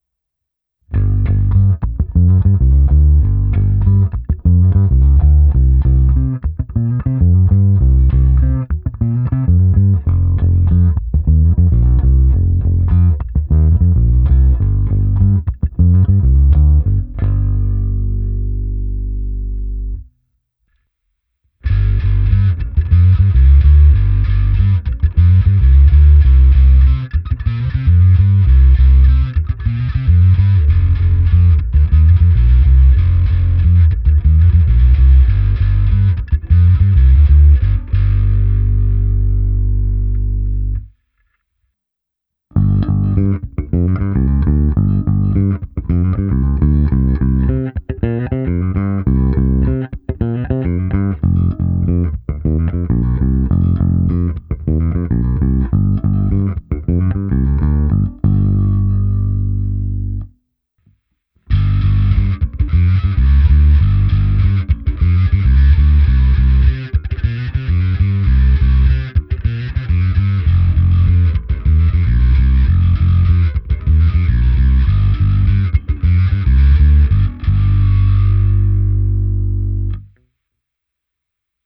Abych simuloval, jak hraje baskytara přes aparát, protáhnul jsem ji preampem Darkglass Harmonic Booster, kompresorem TC Electronic SpectraComp a preampem se simulací aparátu a se zkreslením Darkglass Microtubes X Ultra. Hráno ve stejném pořadí jako výše, s tím rozdílem, že vždy za čistým zvukem je to samé, ale se zkreslením.
Ukázka se simulací aparátu